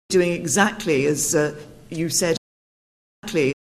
Likewise perfectly, exactly and directly can sound like perfeckly, exackly and direckly:
Lastly, here is Britain’s new Prime Minister Theresa May: